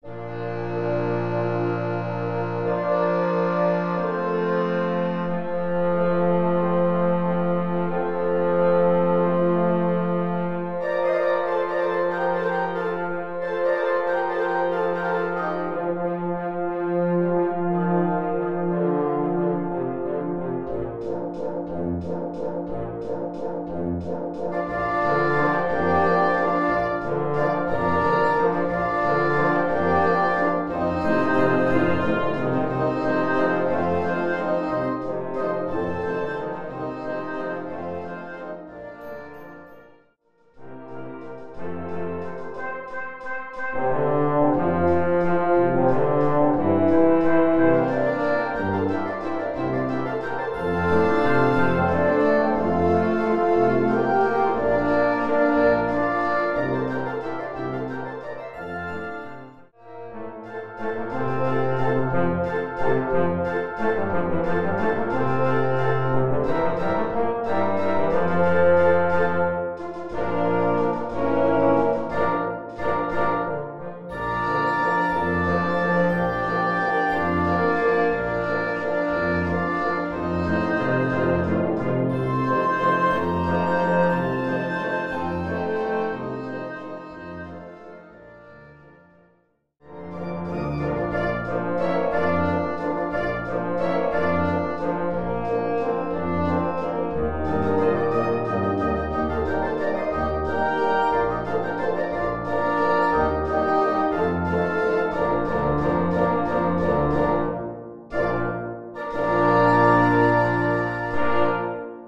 gut klingender und technisch leicht spielbarer Konzertwalzer